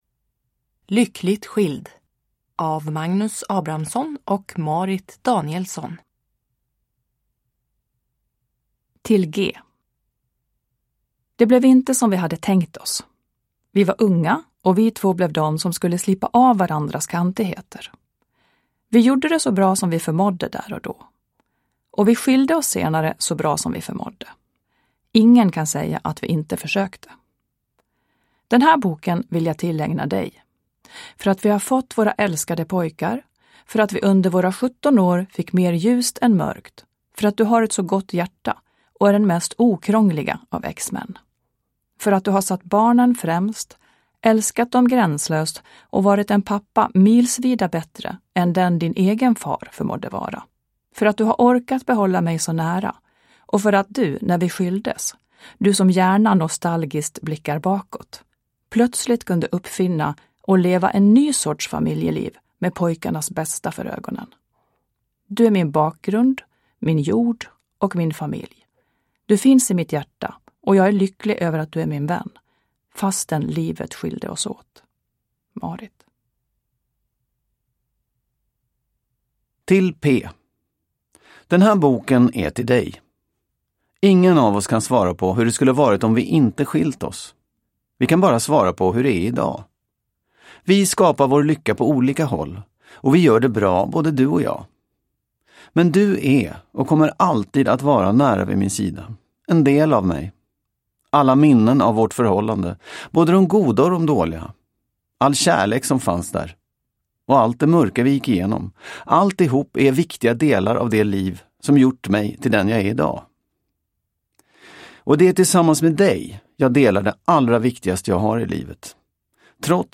Lyckligt skild : Hitta den kloka vägen före, under och efter separationen – Ljudbok – Laddas ner